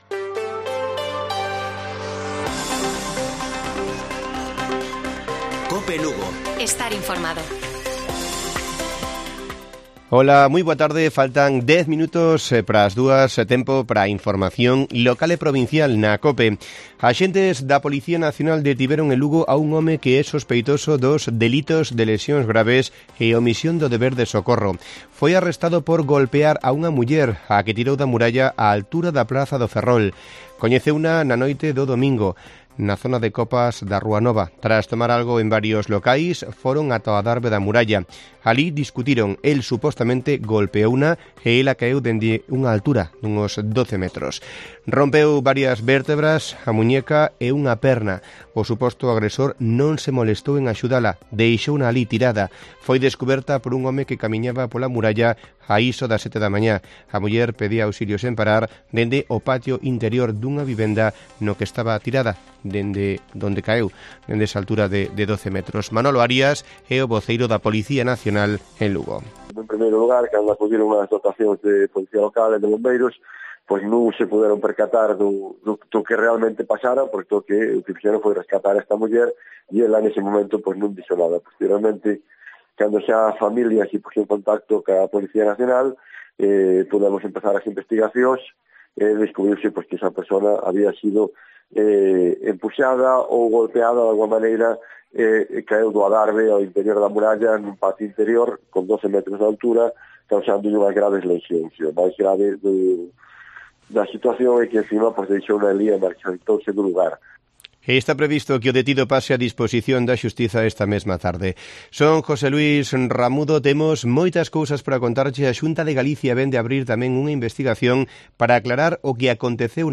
Informativo Mediodía de Cope Lugo, 03 de noviembre de 2021. 13:50 horas